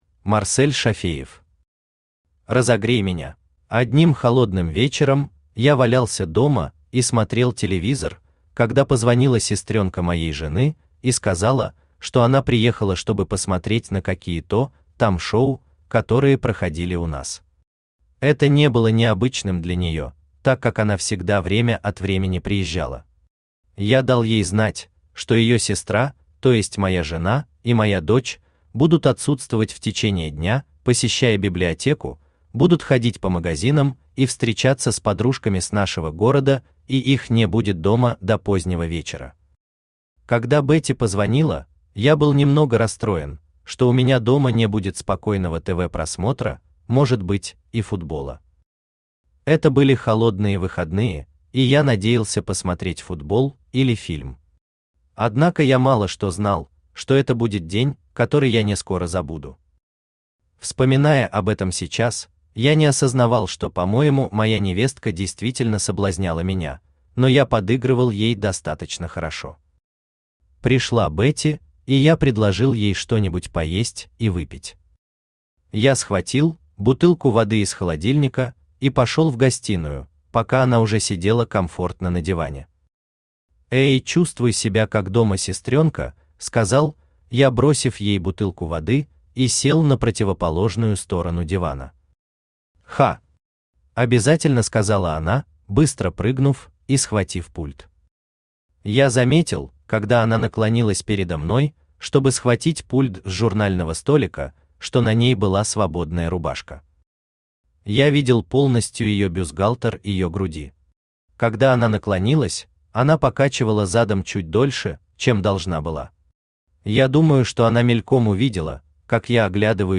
Аудиокнига Разогрей меня | Библиотека аудиокниг
Aудиокнига Разогрей меня Автор Марсель Зуфарович Шафеев Читает аудиокнигу Авточтец ЛитРес.